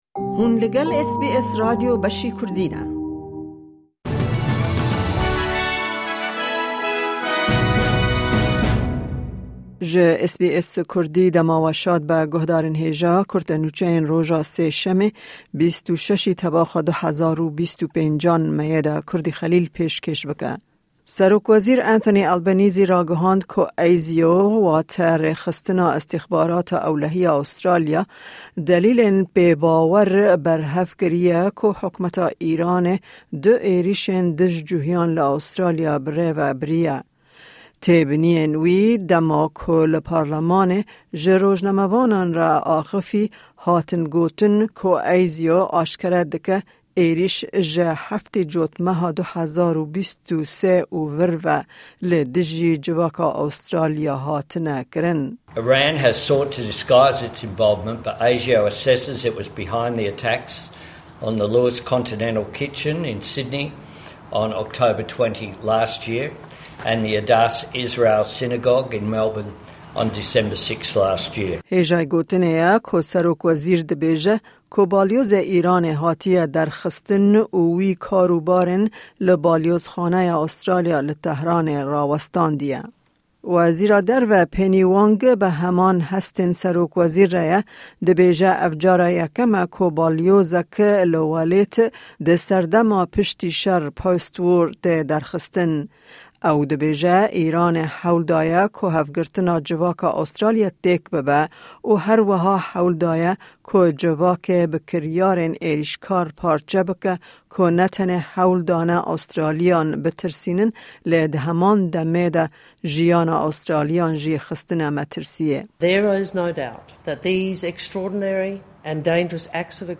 Kurte Nûçeyên roja Sêşemê 26î Tebaxa 2025